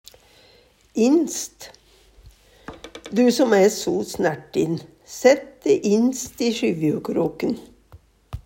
DIALEKTORD PÅ NORMERT NORSK innst innerst Eksempel på bruk Du som æ so snertin, sætt de innst i skjyvjukroken.